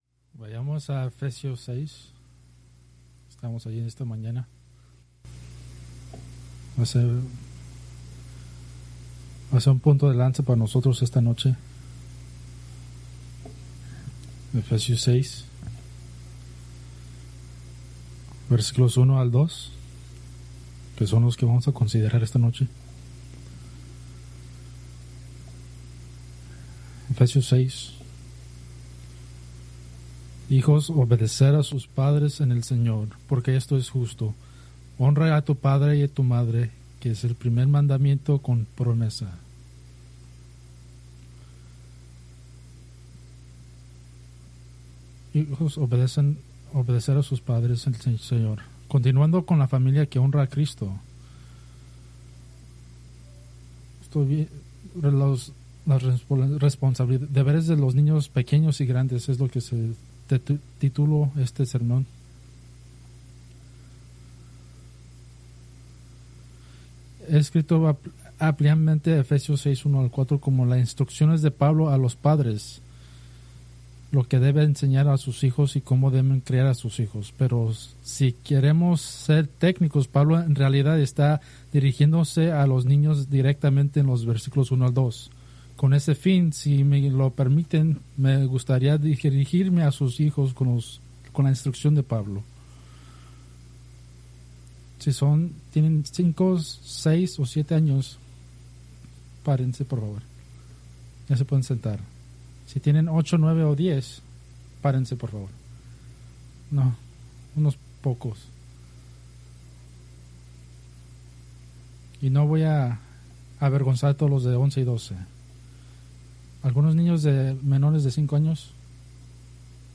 Preached August 24, 2025 from Escrituras seleccionadas